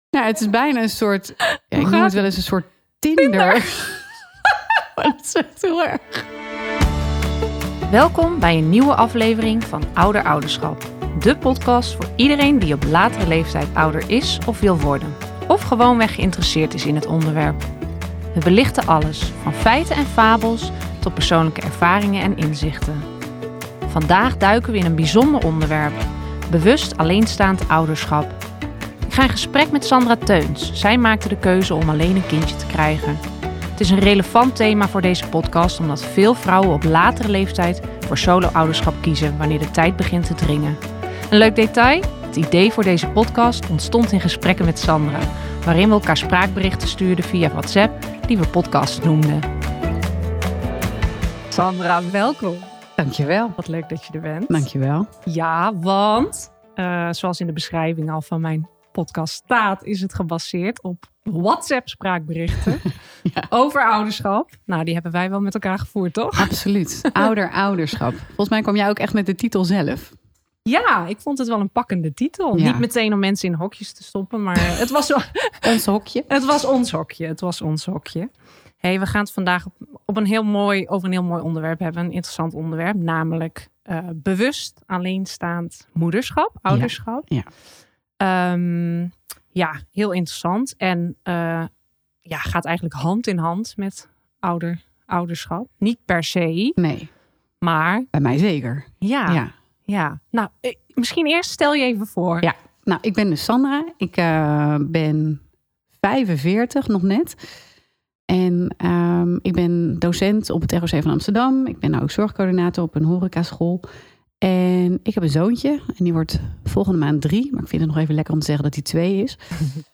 We gaan in gesprek met vrienden, kennissen, experts en ervaringsdeskundigen over de feiten, fabels, uitdagingen en verrassingen die komen kijken bij ouderschap na je 35e.